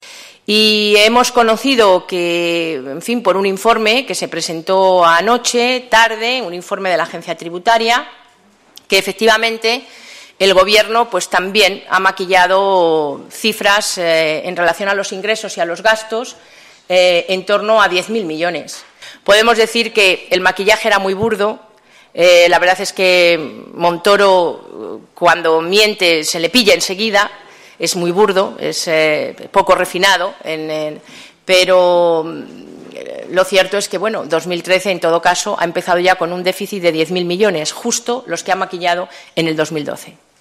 Soraya Rodríguez. Rueda de prensa 12/03/2013